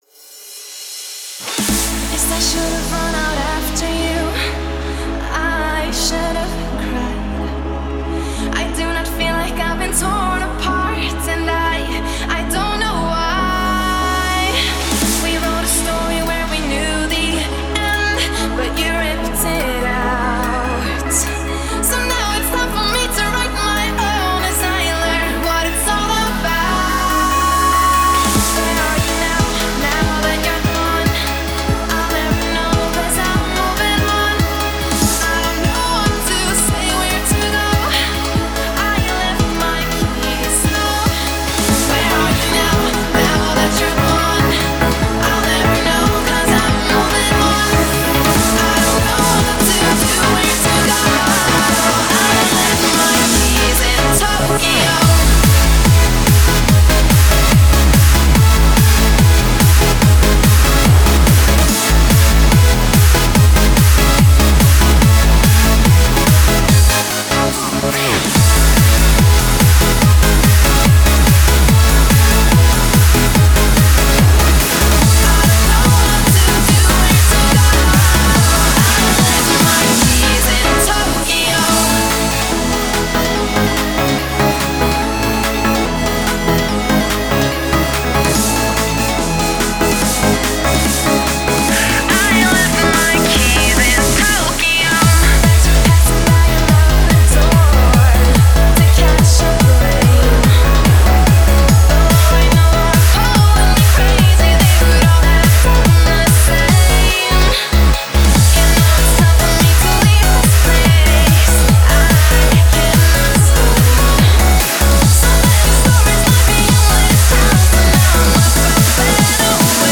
Hands Up song